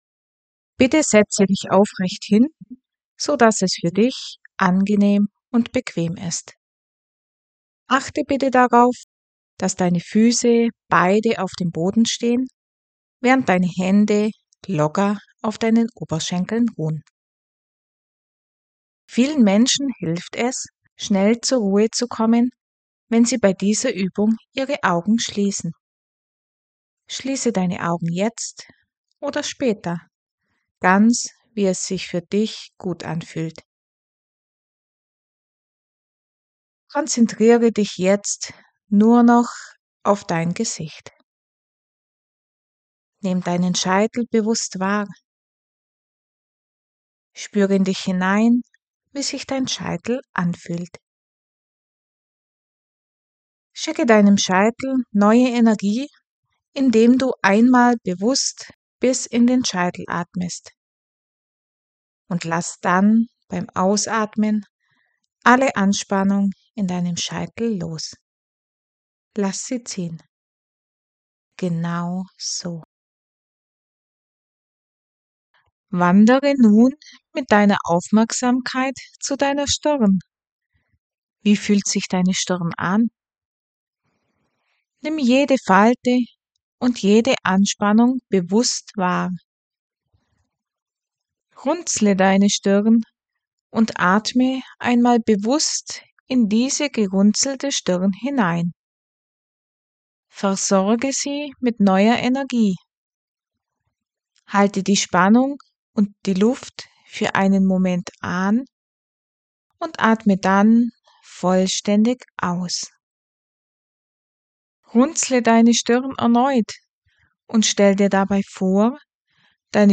Staffel 4 – Hypnose & Meditation zur Tiefenentspannung
kurze, geführte Tiefenentspannung hilft, Stress